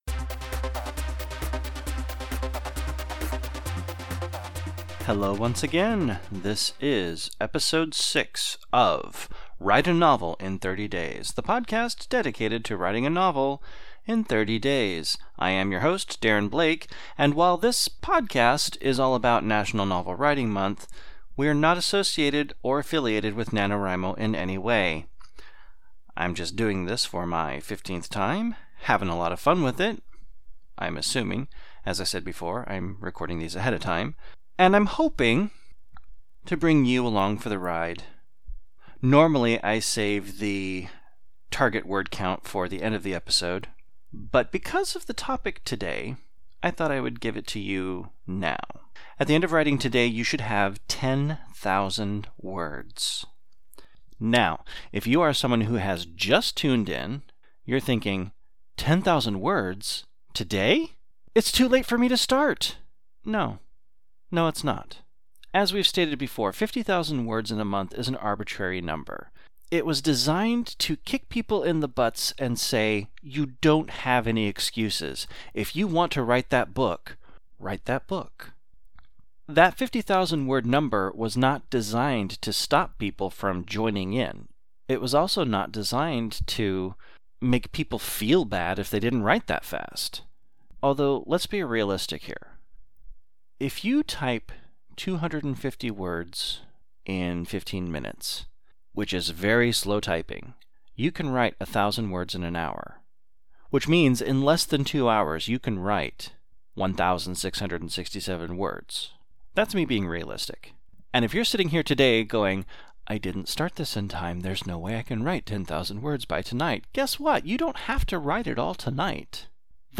Music: “Raving Energy (faster)”